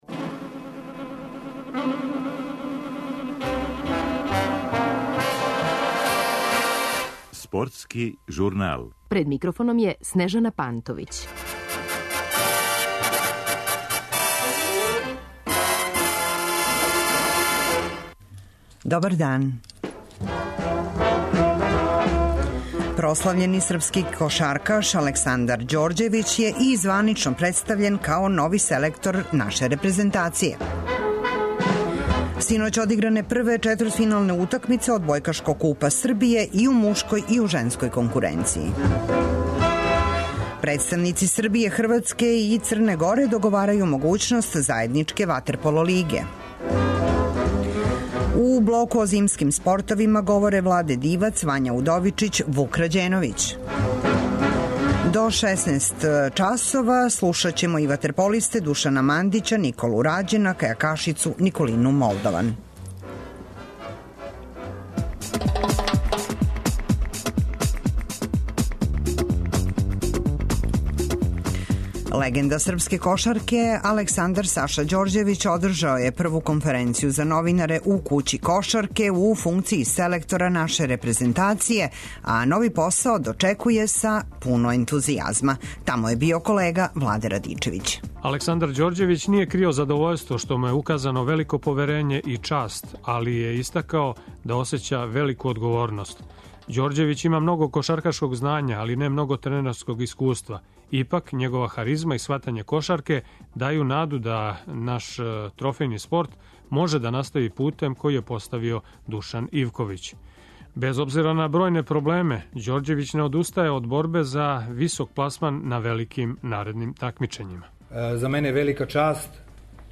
У Спортском журналу о развоју зимских спортова у Србији говоре председник Олимпијског комитета Србије Владе Дивац и министар омладине и спорта Вања Удовичић.